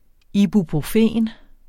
Udtale [ ibupʁoˈfeˀn ]